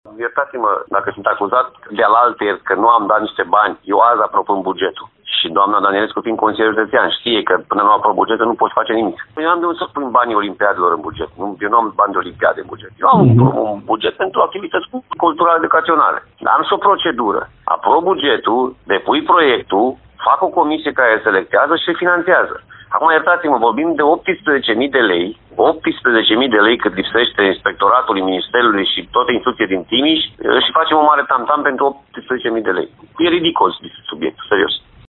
Alfred Simonis, președintele Consiliului Județean spune că instituția nu avea bugetul aprobat în momentul în care Inspectoratul Școlar a cerut sumele respective.